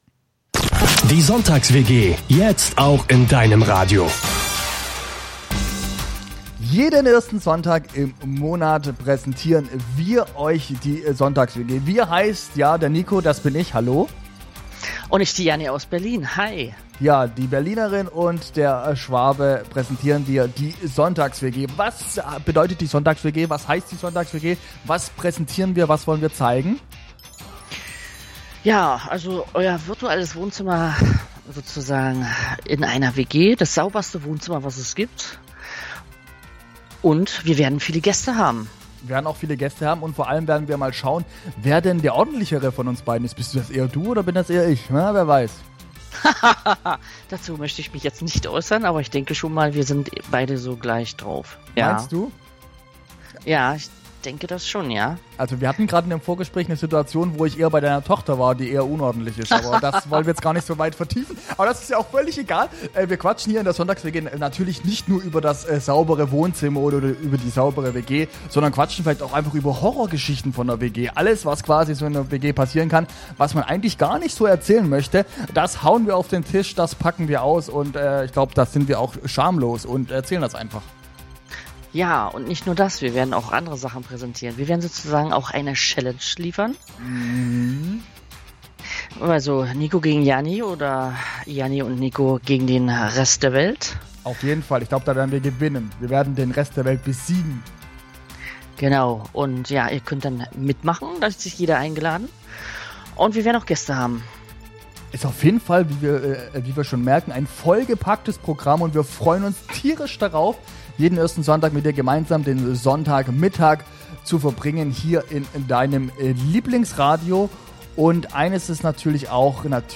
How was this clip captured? Aircheck-Die-Sonntags-WG.mp3